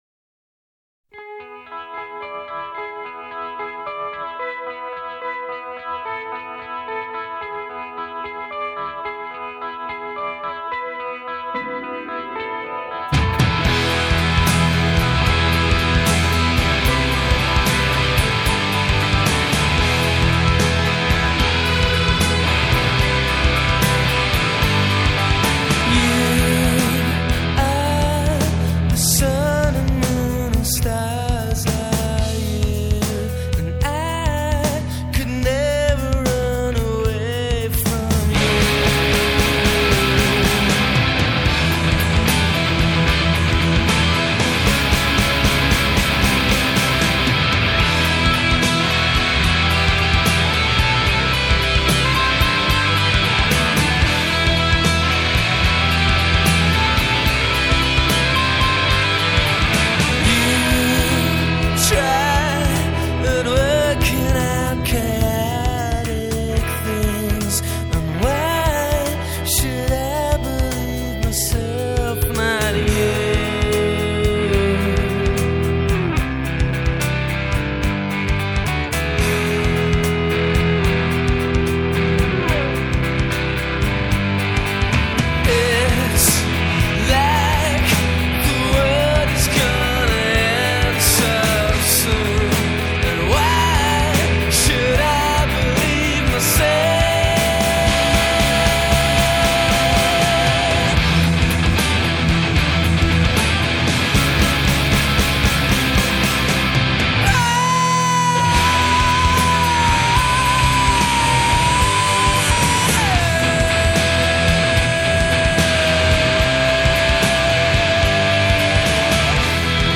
Alternative Rock, Grunge